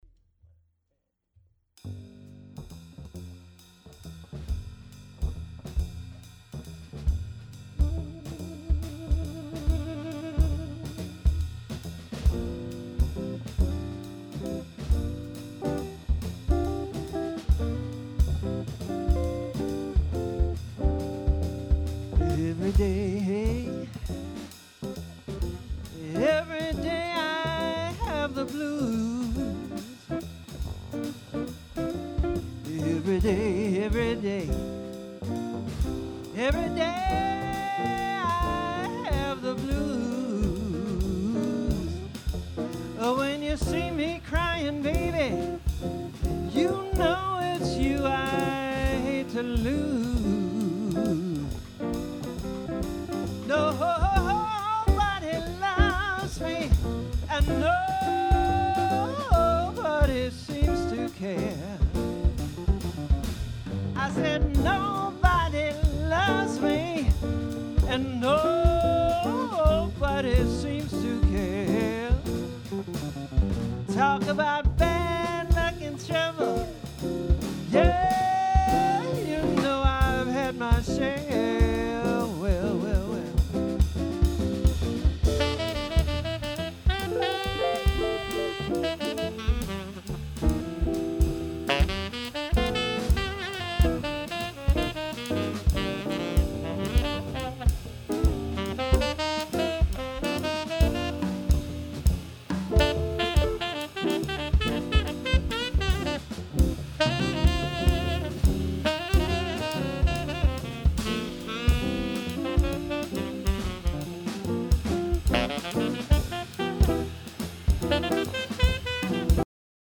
Sax and Vocals
Drums
Bass
Guitar